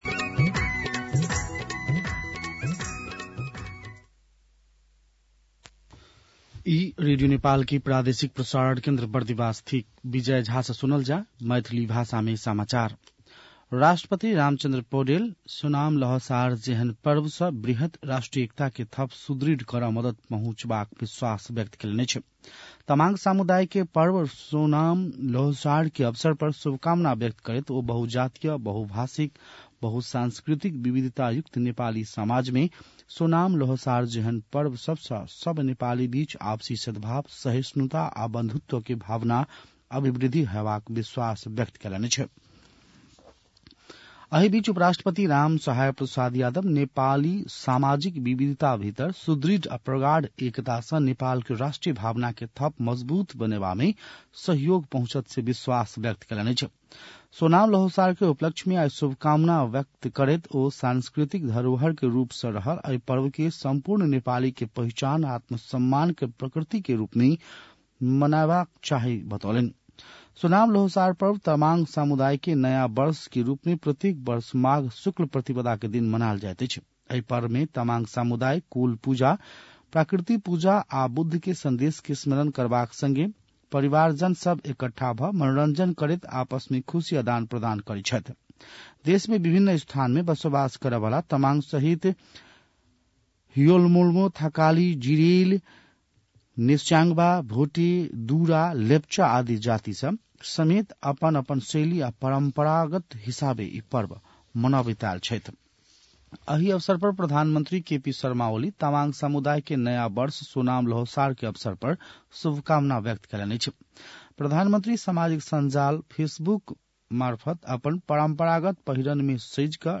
मैथिली भाषामा समाचार : १८ माघ , २०८१
Maithali-news-10-17.mp3